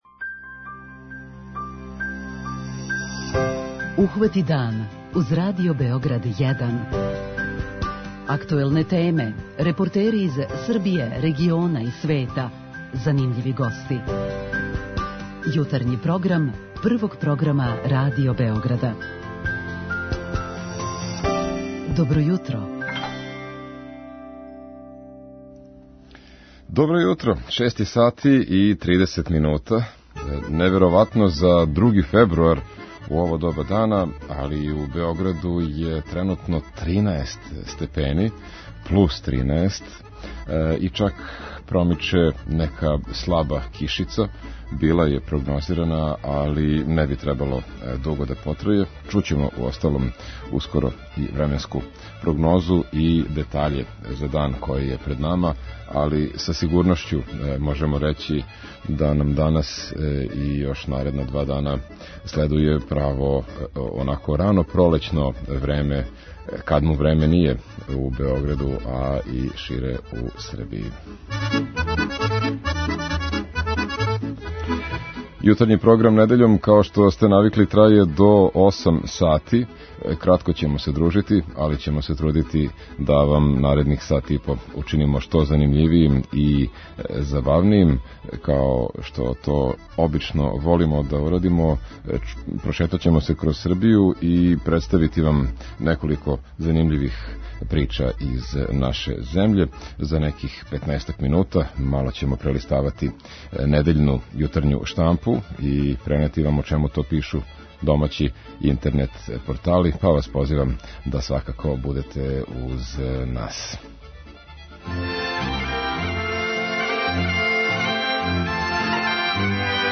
Чућете репортажу о школи у Каони која је стара 152 године, причу о јединој сточној пијаци у Ристовцу која ради једном недељно, као и редовну рубрику 'Књига солидарности' - о најстаријој програмској акцији Радио Београда. Наравно, ту су и уобичајене сервисне информације и подаци о стању у саобраћају.